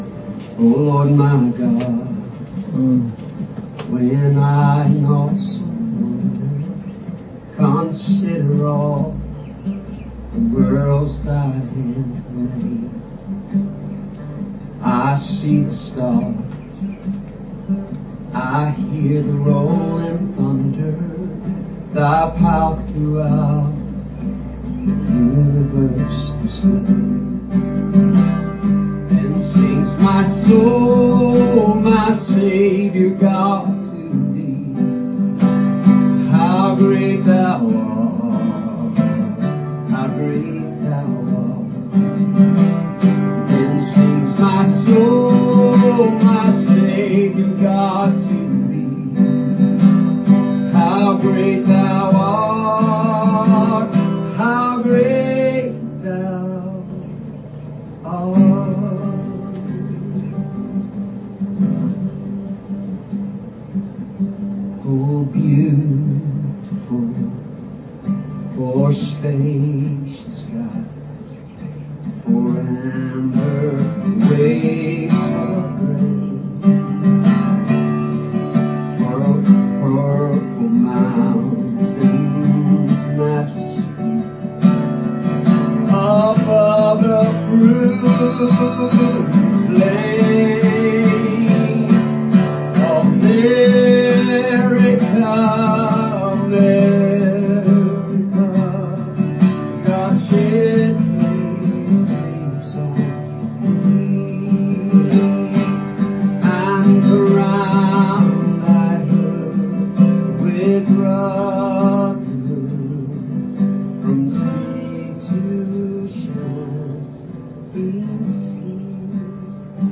Special Music
Medley chosen for this Memorial Sunday service